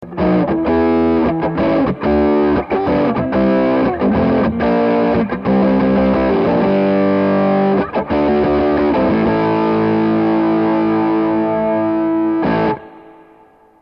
Fuzz Chords
fuzz.mp3